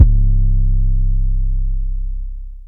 YM 808 12.wav